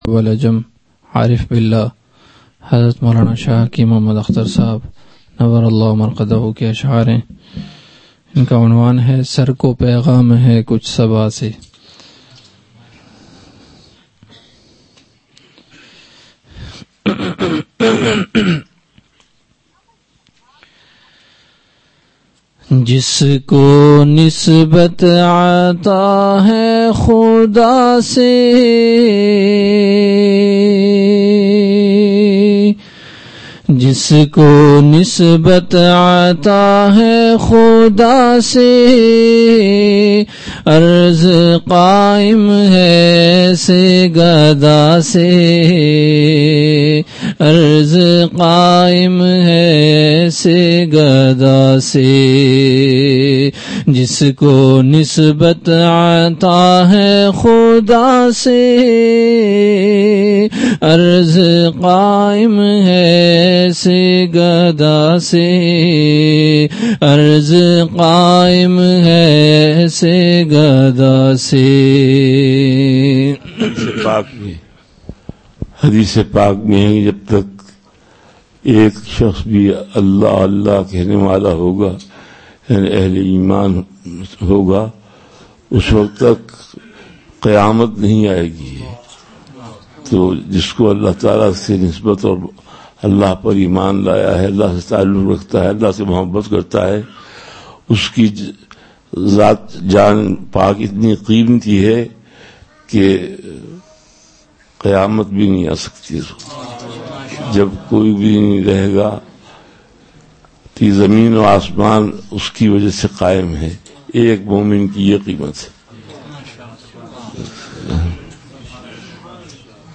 اصلاحی مجلس کی جھلکیاں(کلک کریں)
اشعاربہت ہی عمدہ انداز میں سنا ئے
ملفوظات پڑھنے شروع کیے